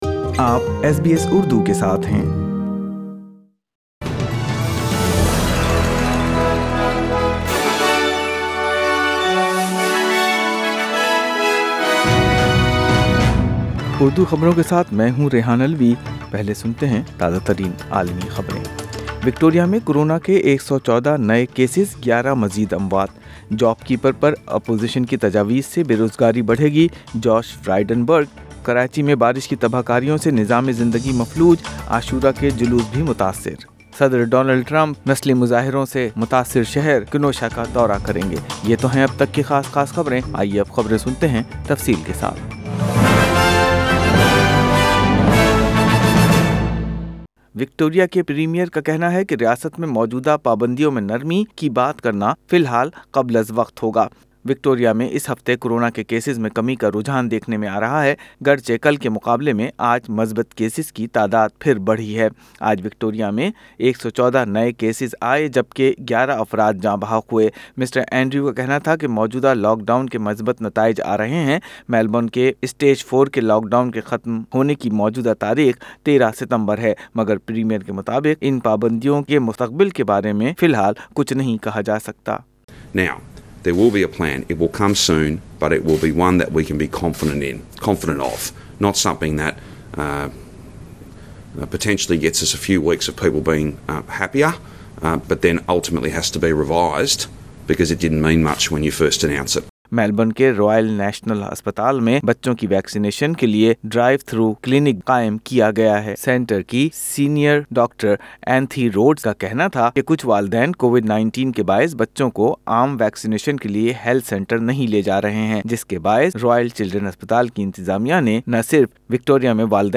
کراچی سمیت سندھ کے کئی ضلعوں کو طوفانی بارشوں کے بعد آفت زدہ قرار دے دیا گیا۔ سنئے اتوار 30 اگست 2020 کی اردو خبریں